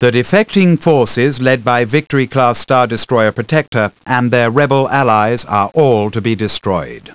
Briefings och mycket av radiotrafiken är nu inläst på - Halleluja! - brittisk engelska!
I vilket fall har jag, för att oinitierade ska kunna ta del av denna njutning, letat rätt på två passager från två briefings med destruktiv inriktning, konverterat dem till wav-filer och lagt dem alldeles här! 8 bitar, 22 kHz mono.